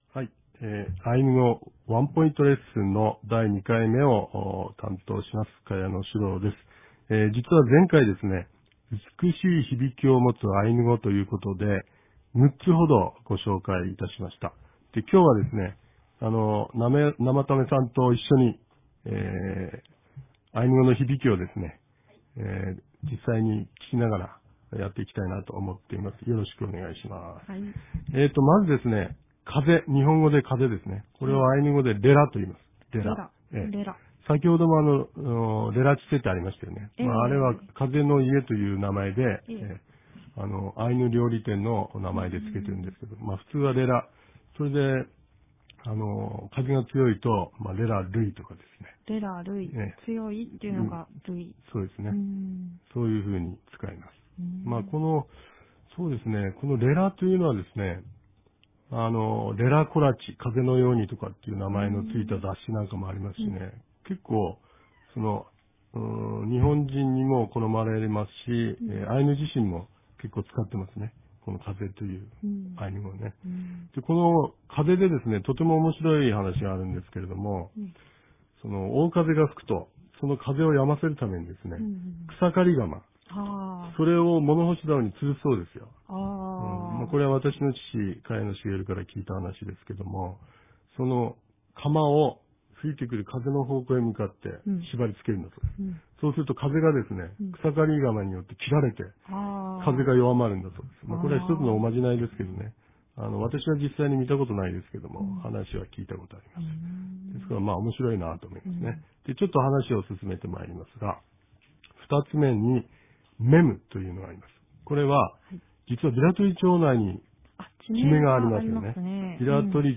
地域のニュース